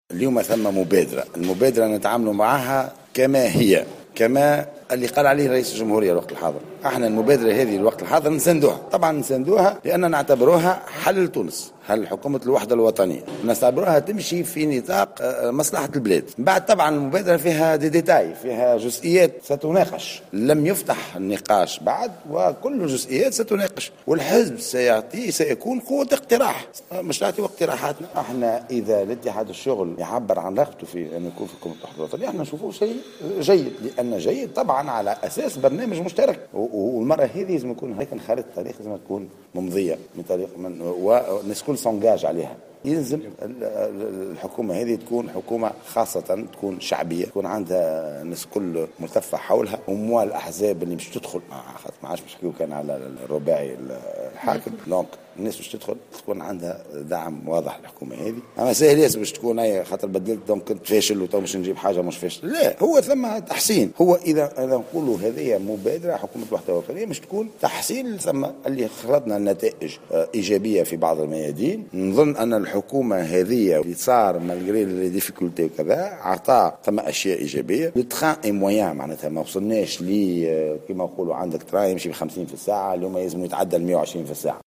واعتبر القيادي في حزب آفاق تونس رياض الموخر في تصريح للجوهرة أف أم اليوم الأحد 5 جوان 2016 أن هذه المبادرة فيها حل ومصلحة لتونس معربا عن استعداد حزبه لفتح قنوات الحوار مع كل الأطراف المعنية بهذه المبادرة من بينها الاتحاد العام التونسي للشغل.